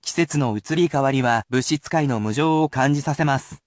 I will also read this aloud, but it is usually at regular speed, and is usually very specific, so you need not repeat if it is too fast.
[polite speech]